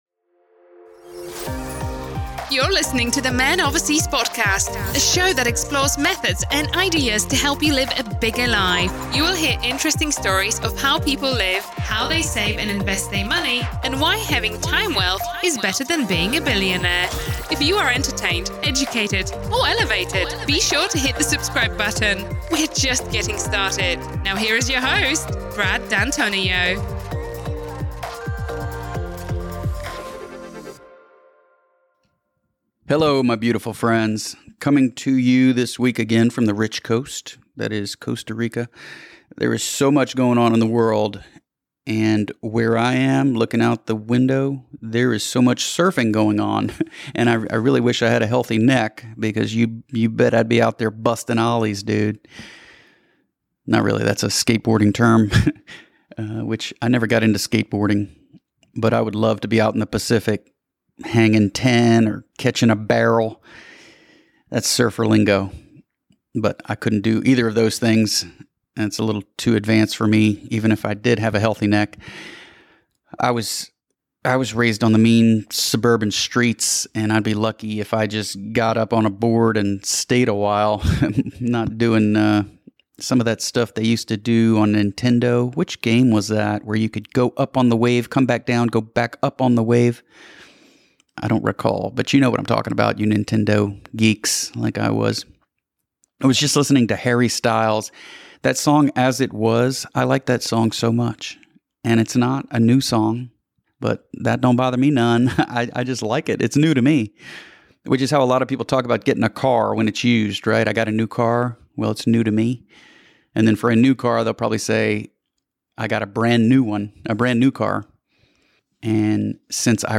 Coming to you solo again from beautiful Costa Rica, which means “rich coast.”